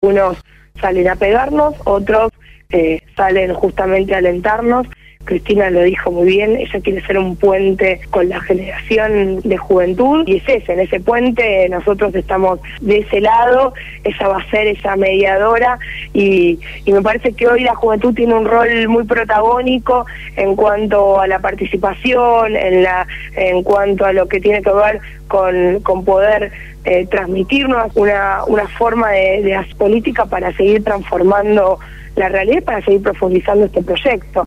Victoria Colombo, referente de la agrupación La Cámpora y Comunera electa en la Comuna 4 por el Frente Para la Victoria habló en el programa Punto de Partida de Radio Gráfica FM 89.3 con motivo del Festival por el Día del Niño que se realizará a partir de las 14 horas en Iriarte y Vélez Sarfield, en el Barrio de Barracas de la Ciudad de Buenos Aires.